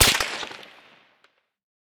med_crack_04.ogg